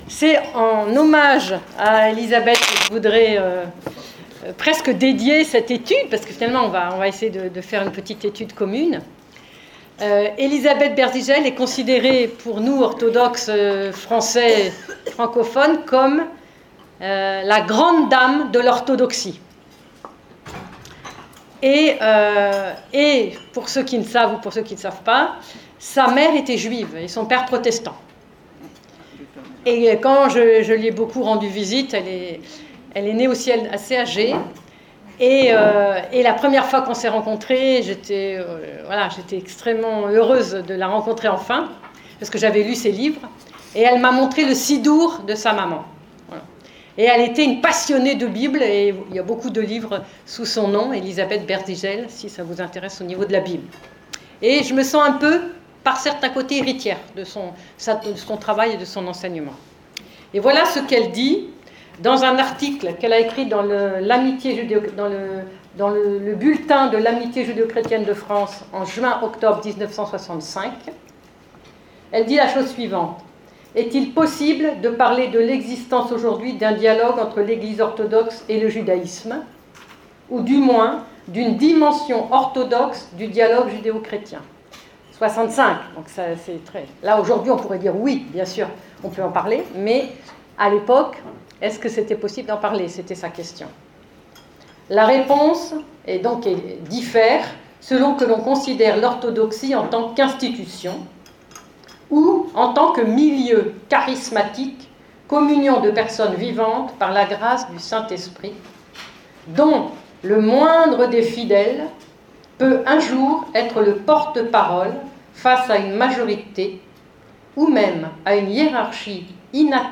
La conférence est aussi disponible en vidéo